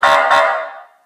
Звуки полицейской крякалки
Короткий звук крякалки ДПС